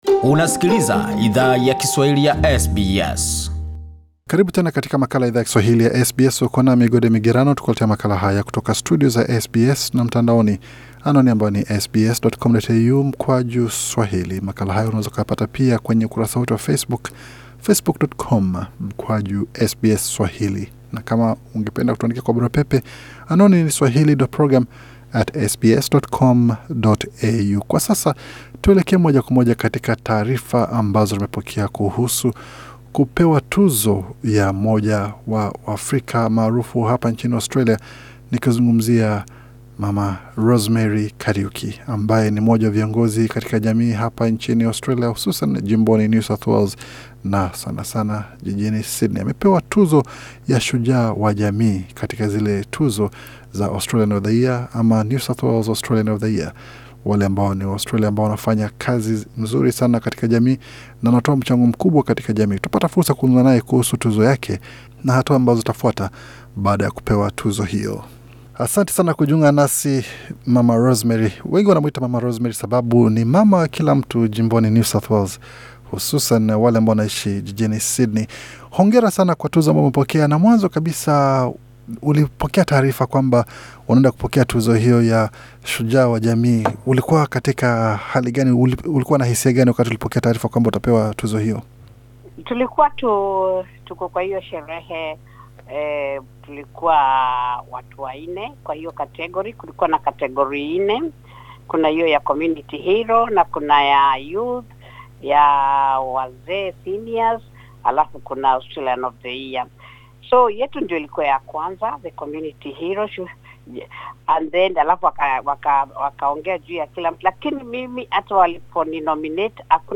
Katika mazungumzo maalum na Idhaa ya Kiswahili ya SBS